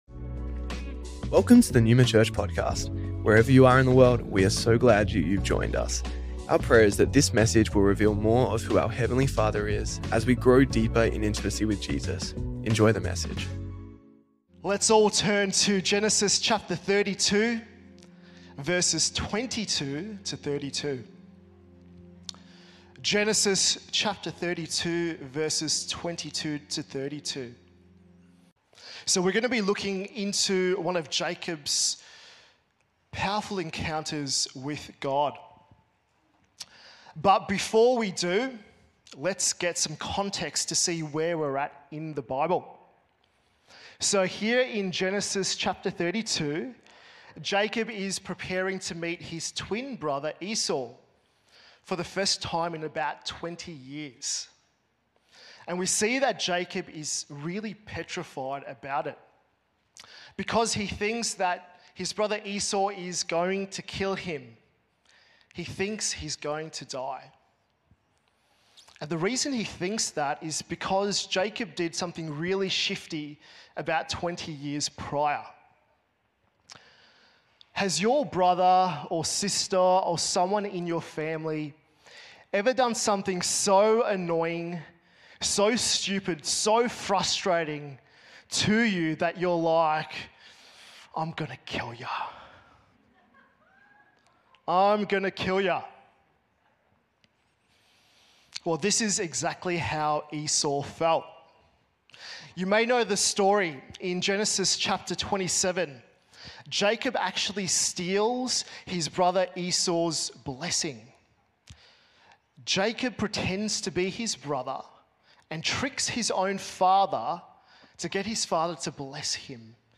Originally Recorded at the 10AM Service on Sunday 29th December 2024.